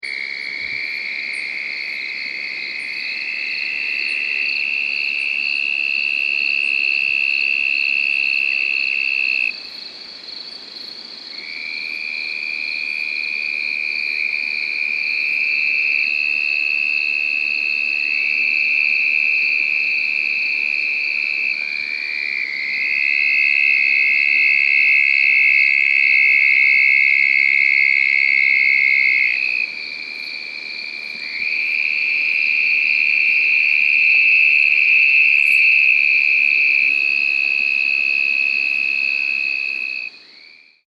The call of the Red-spotted Toad is a prolonged loud high-pitched musical trill, lasting up to 10 seconds, which is produced at night .
Sound This is a 41 second recording of a group of toads calling at night in Brewster County, Texas near the Rio Grande River.
bpunctatustx07chorus.mp3